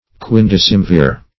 Search Result for " quindecemvir" : The Collaborative International Dictionary of English v.0.48: Quindecemvir \Quin`de*cem"vir\, n.; pl.
quindecemvir.mp3